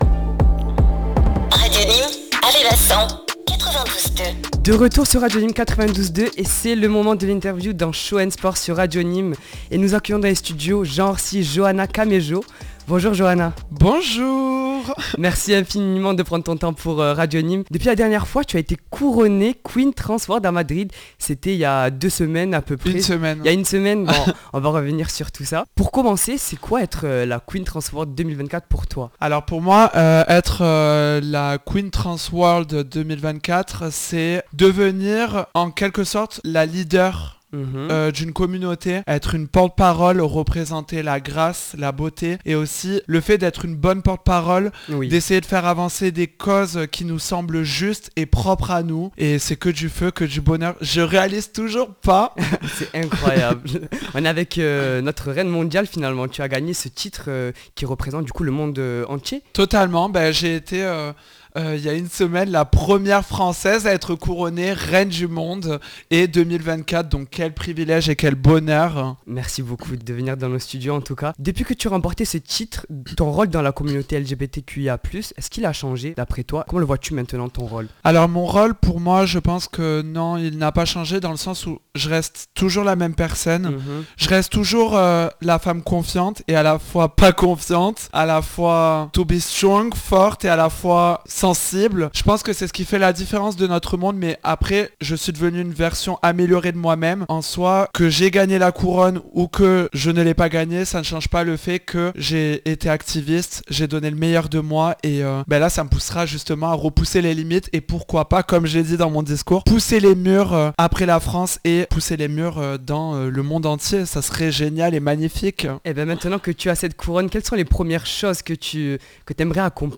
INTERVIEW QUEEN TRANS WORLD